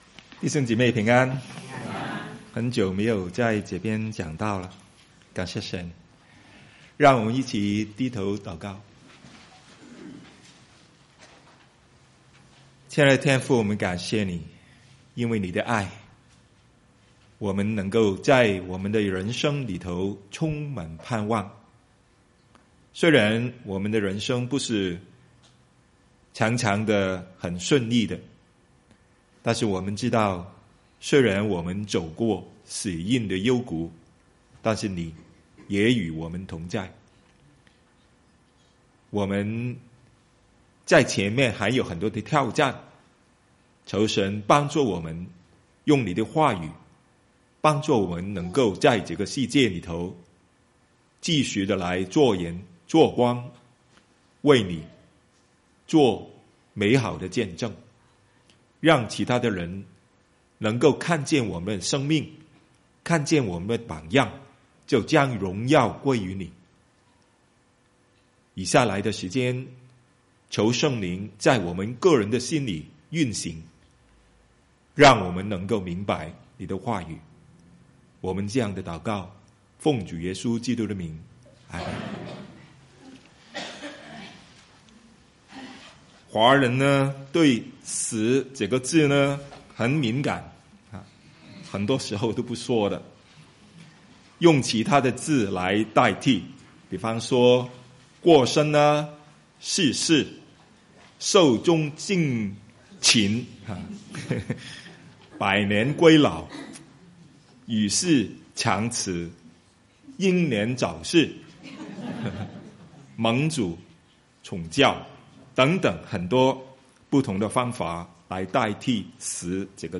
Sermons | Fraser Lands Church